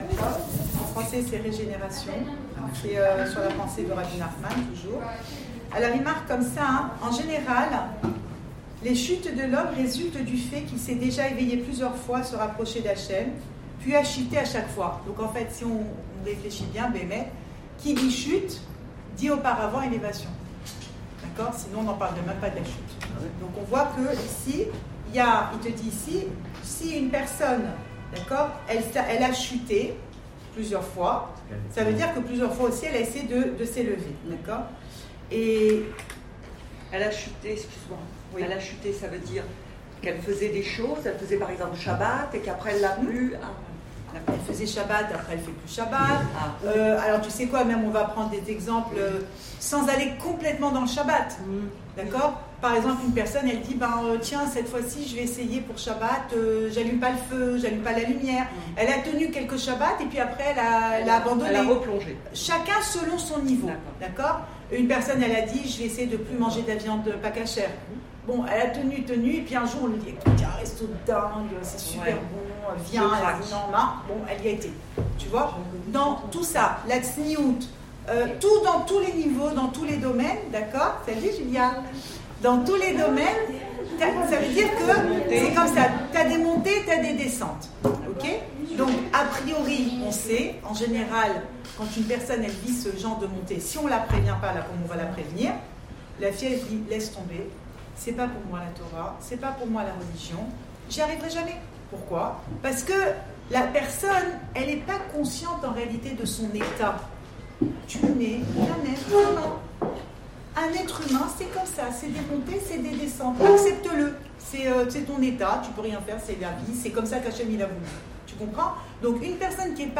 Cours audio Emouna Le coin des femmes Pensée Breslev - 15 janvier 2020 24 janvier 2020 Parce qu’Il retient ‘l’essai’… Enregistré à Tel Aviv